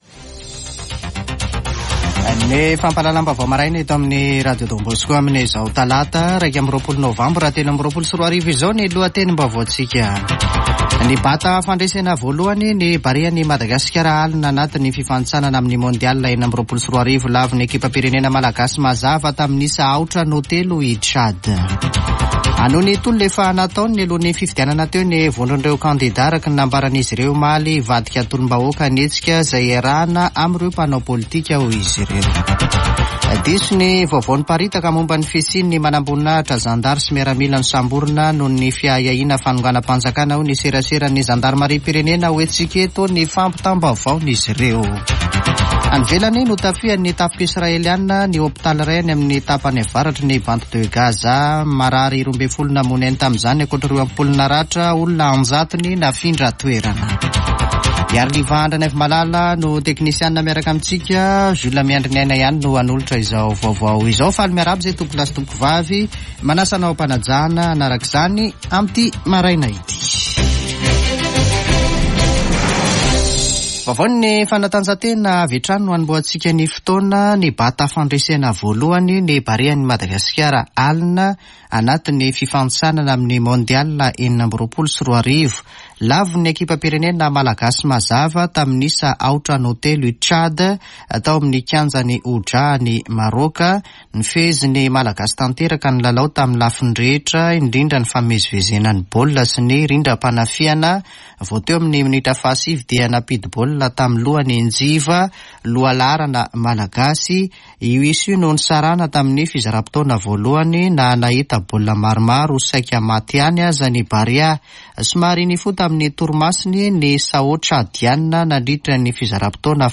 [Vaovao maraina] Talata 21 nôvambra 2023